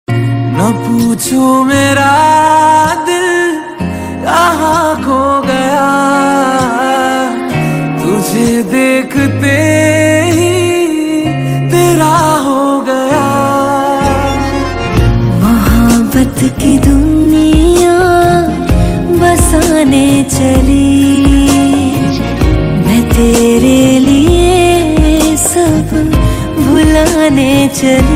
emotional and romantic